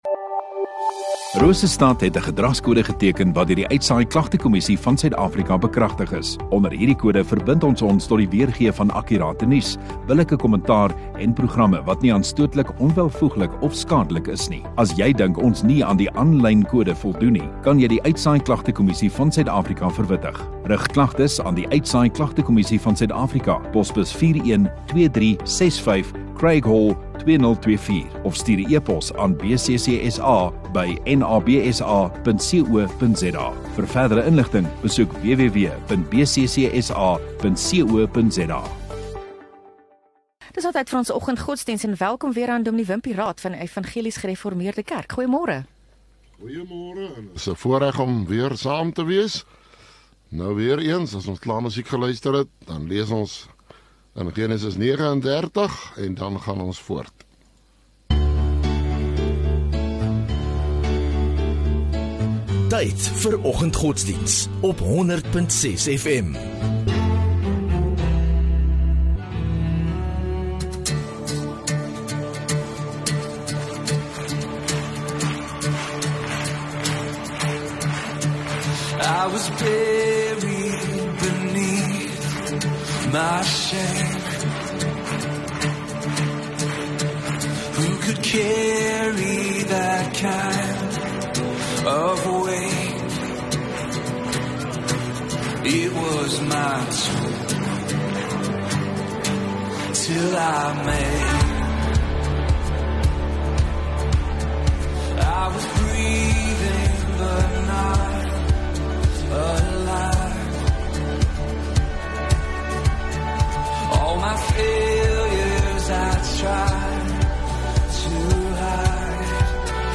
12 Jun Woensdag Oggenddiens